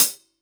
Closed Hats
HIHAT773.WAV